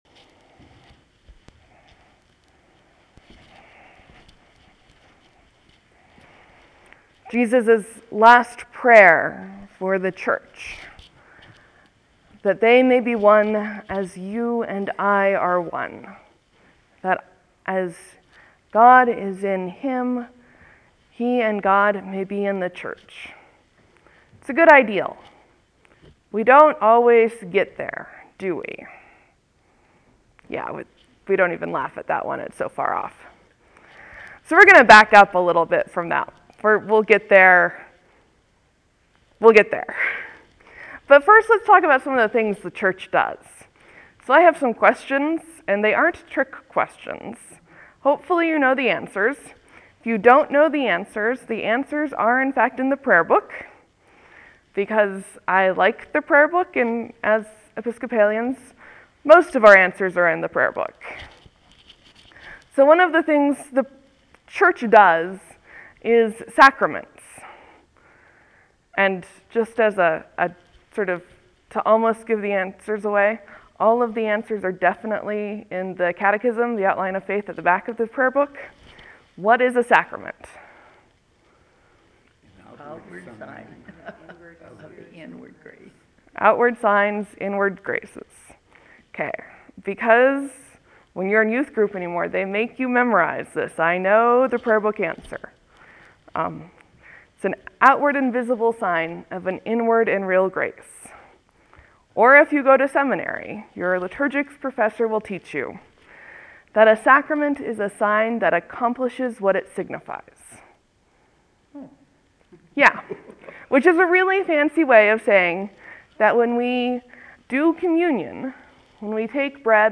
The Church, Sacraments, and Unity: sermon for Easter 7 5-12-13 (audio)
An Anglican/Episcopal priest, bibliophile, dog owner, and Montanan